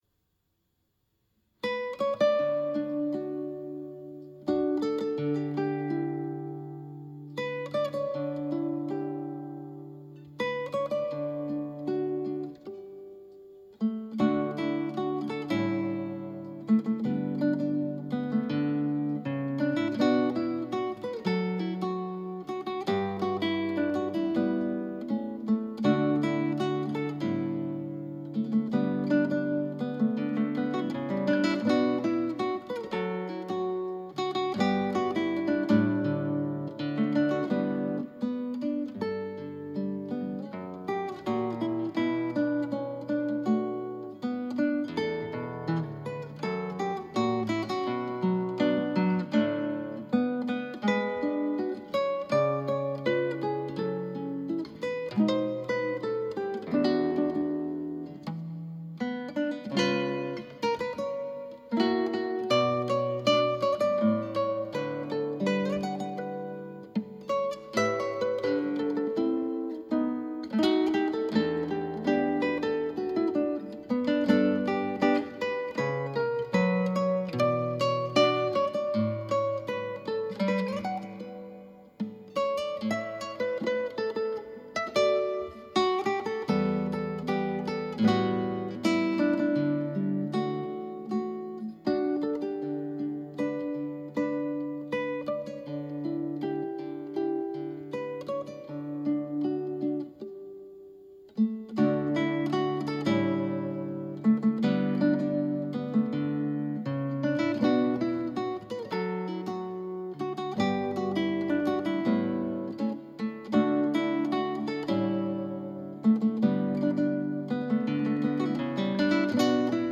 ギターの自演をストリーミングで提供